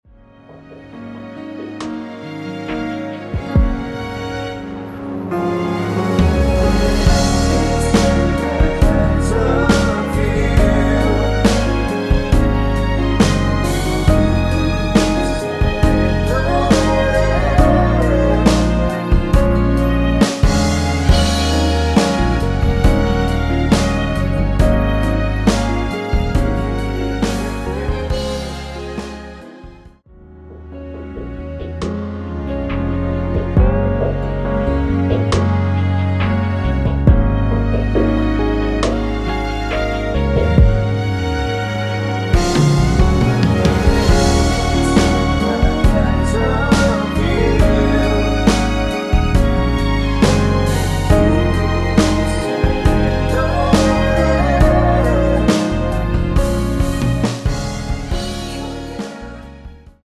원키에서(-2)내린 코러스 포함된 MR입니다.(미리듣기 확인)
Db
앞부분30초, 뒷부분30초씩 편집해서 올려 드리고 있습니다.